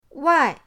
wai4.mp3